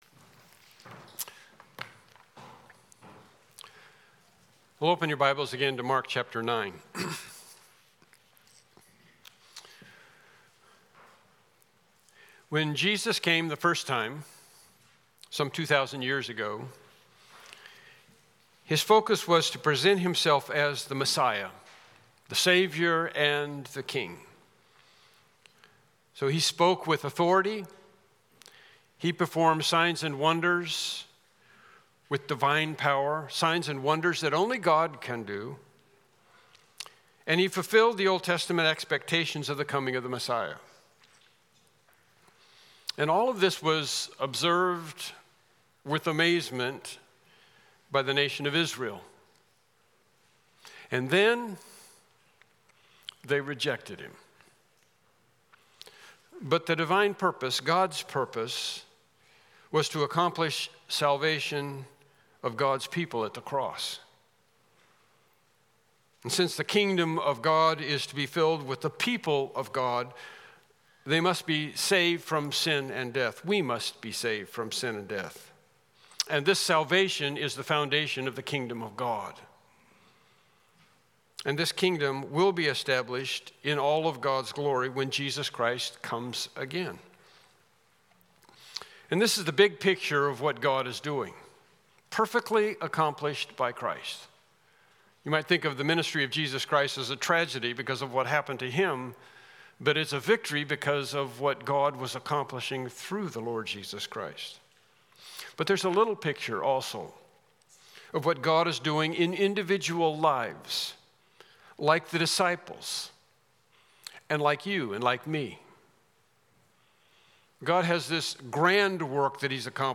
Unknown Service Type: Morning Worship Service « Lesson 17